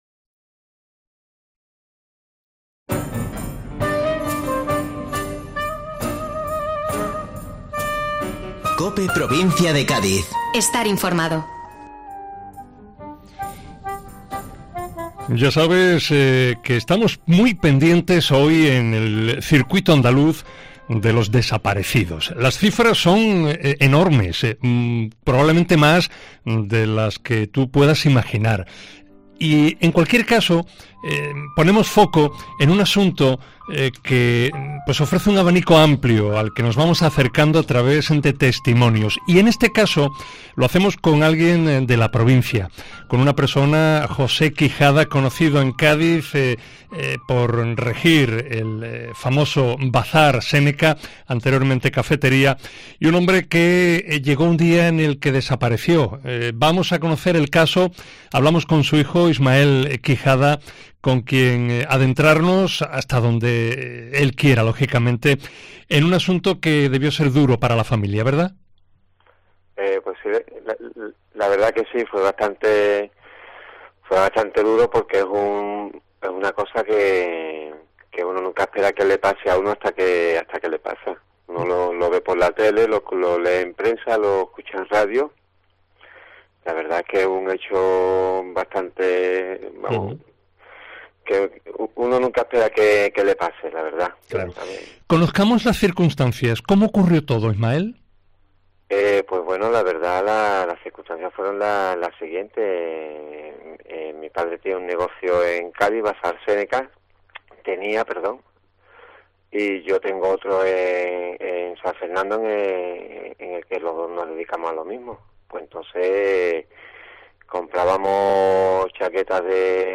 La Policía sigue intentando esclarecer qué ocurrió. Hemos hablado, en Mediodía Cope Provincia de Cádiz